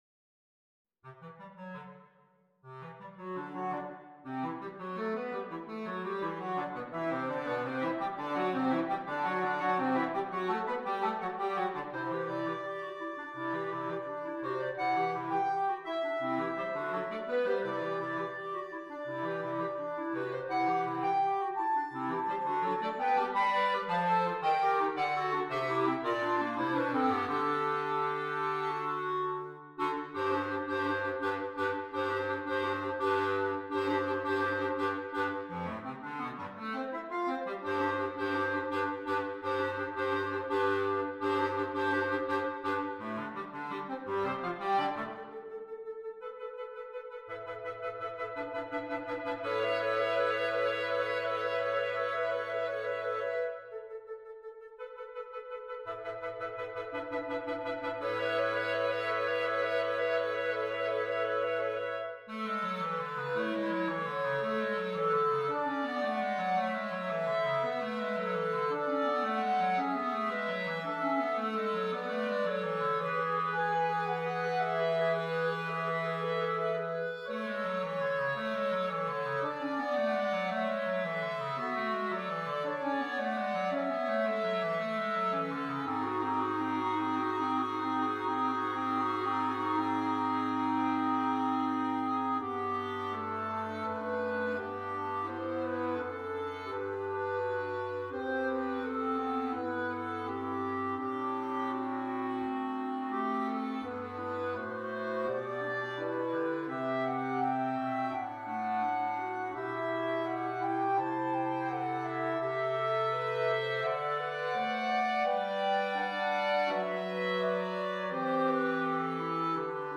3 Clarinets, Bass Clarinet